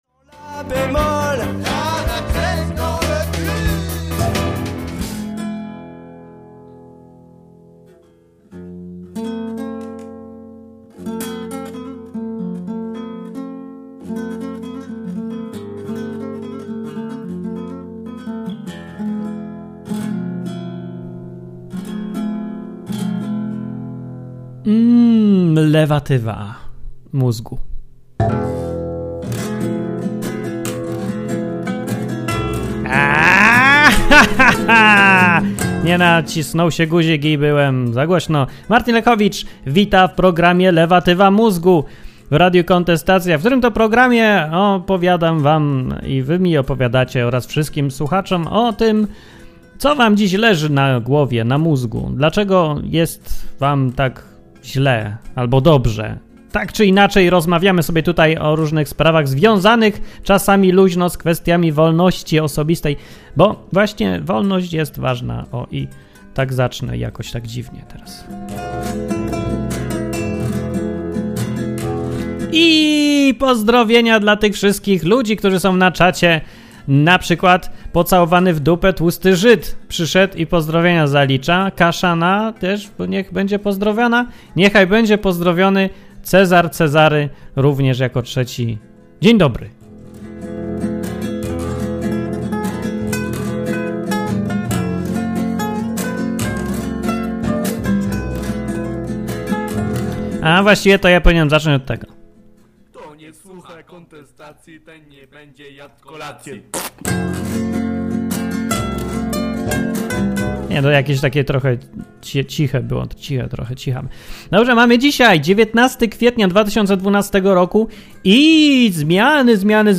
Były informacje, komentarze, słuchacze, wszystko co najlepsze.